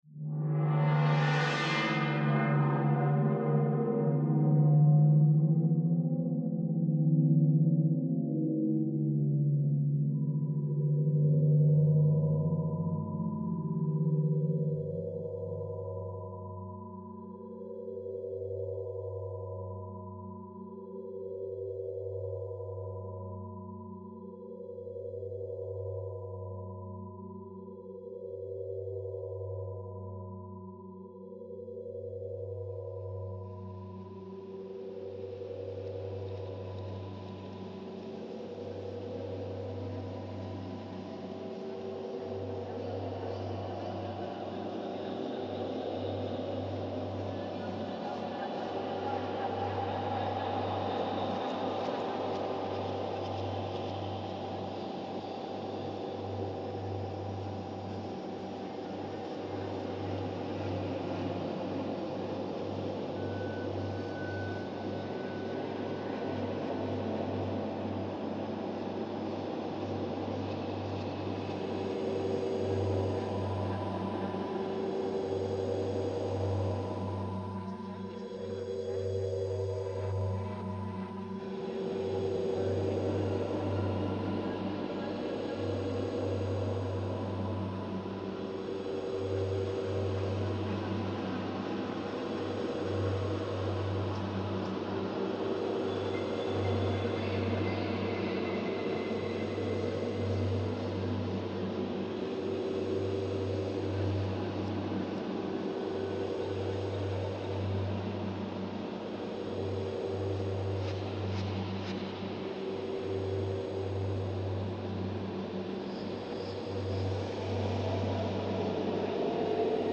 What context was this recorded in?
Budapest Central Market Hall reimagined